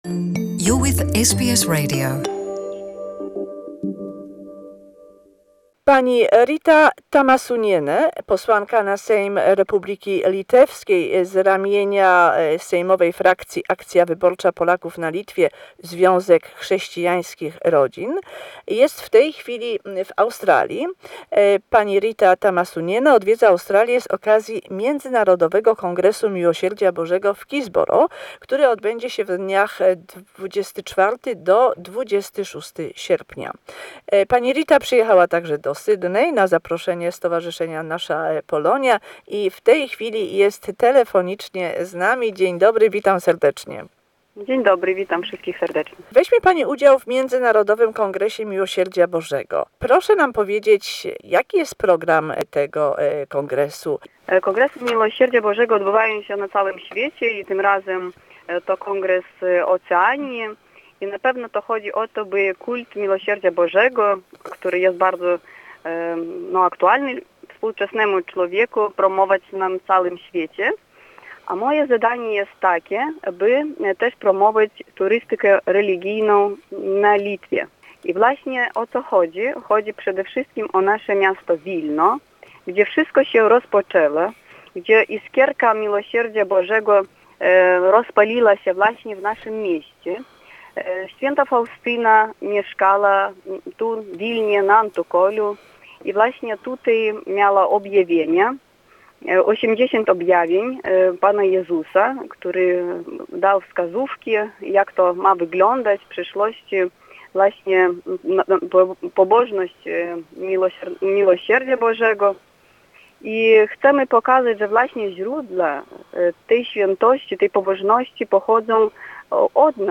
An Interview with Rita Tamasuniene from Lithuanian's Parliament.